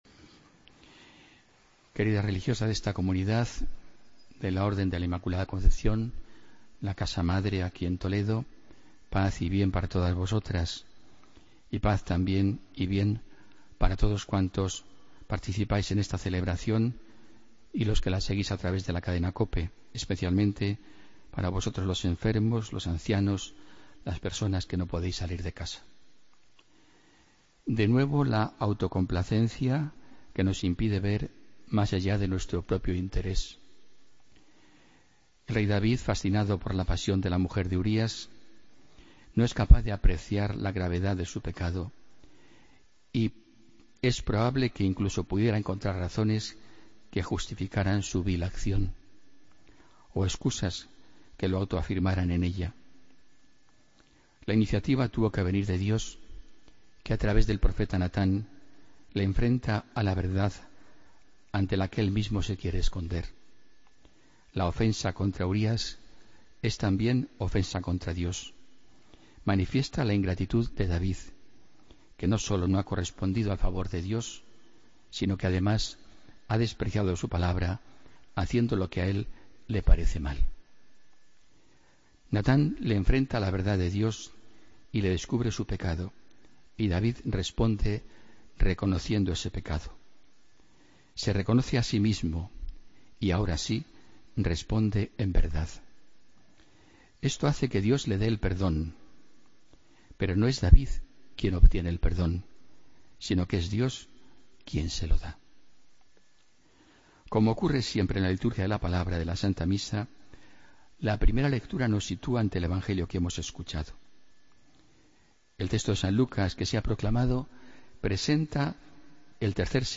Homilía, domingo 12 de junio de 2016